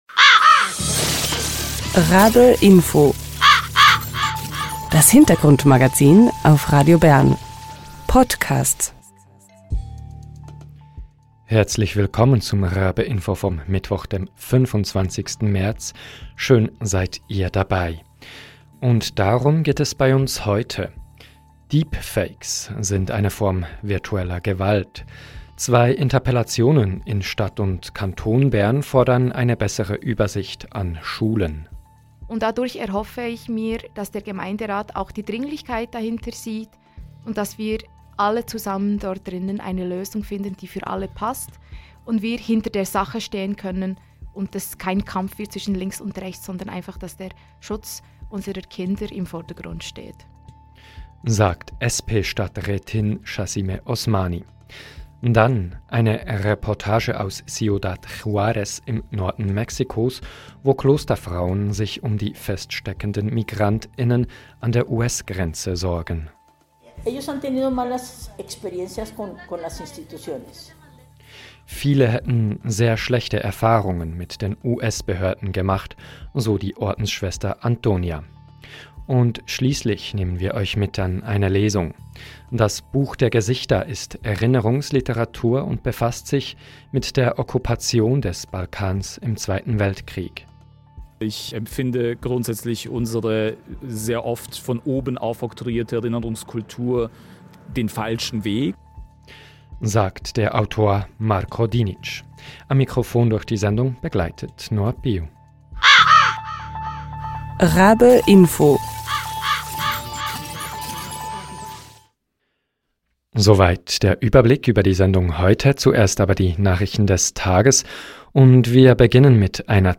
Die Situation in den Grenzstädten ist angespannt und von Razzien und Abschiebungen geprägte, wie die Reportage aus Ciudad Juárez zeigt.